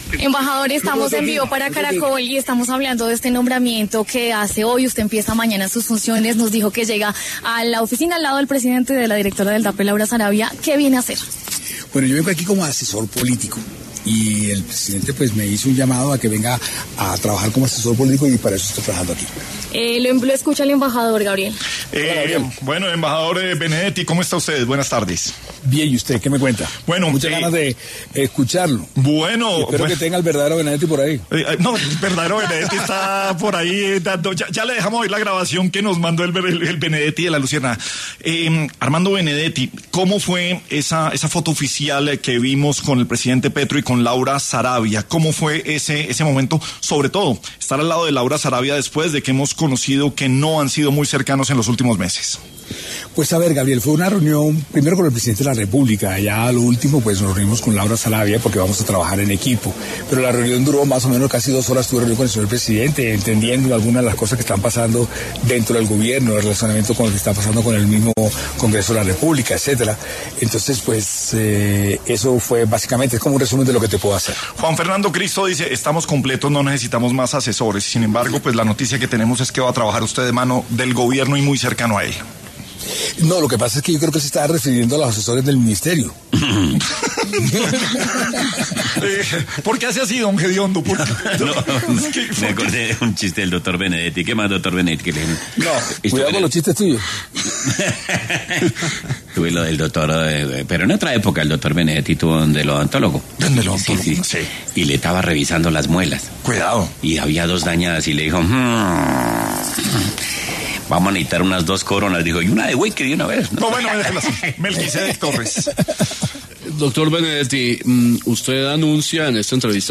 El exembajador de Colombia ante la Organización de las Naciones Unidas para la Alimentación y la Agricultura (FAO), Armando Benedetti, estuvo tras los micrófonos de ‘La Luciérnaga’ para abordar y ampliar la información sobre el nuevo cargo que tendrá desde mañana en el gobierno de Gustavo Petro.